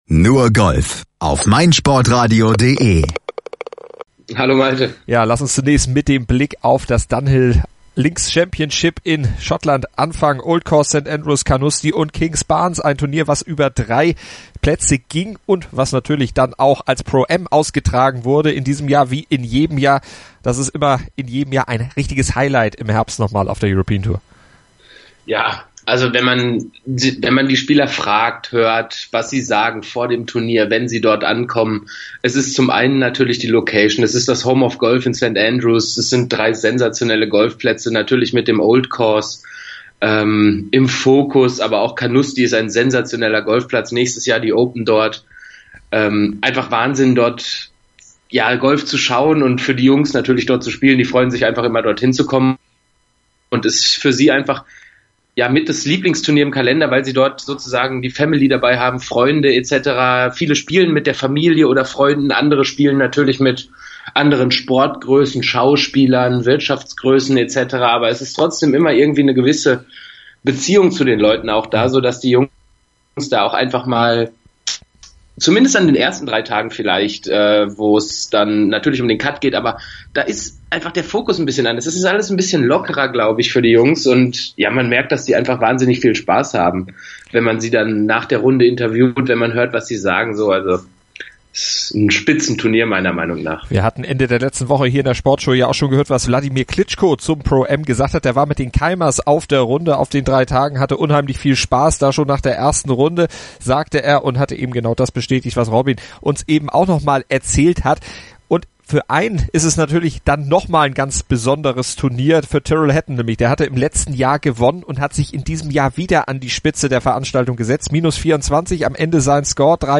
Ihr ging es in dieser Woche um viel mehr als um Golf, wie sie im sehr emotionalen Siegerinterview erklärte.